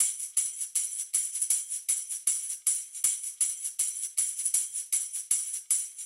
Index of /musicradar/sampled-funk-soul-samples/79bpm/Beats
SSF_TambProc1_79-02.wav